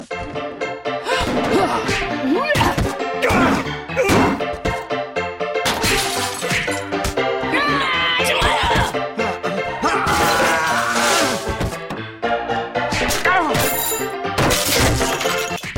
Weiß jemand wie die Art von Musik heißt, die in Stummfilmen bei Chaos-Szenen/Schlägereien und so lief? Mit dem Klavier gespielt, sehr hektisch.